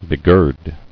[be·gird]